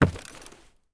328d67128d Divergent / mods / Soundscape Overhaul / gamedata / sounds / material / human / step / new_wood2.ogg 33 KiB (Stored with Git LFS) Raw History
new_wood2.ogg